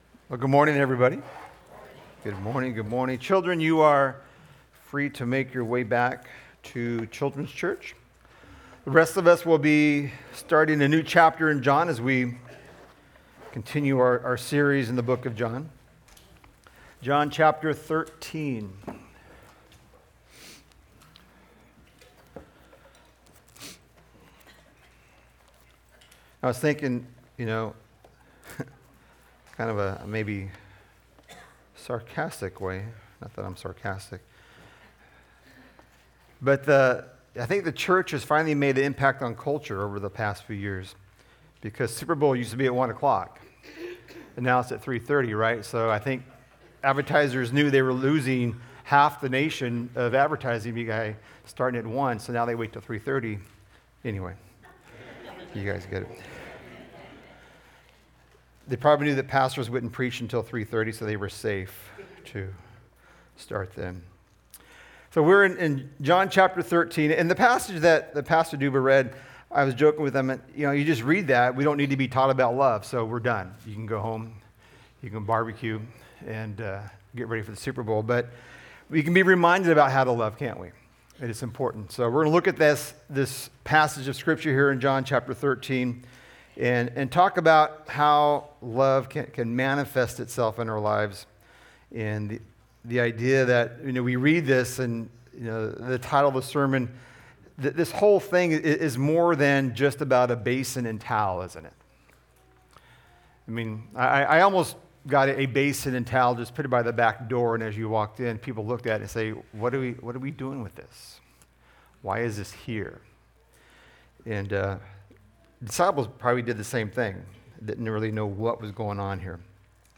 In our passage today, Jesus gives us a perfect example to pattern our lives after. Sermon Notes:Coming soon.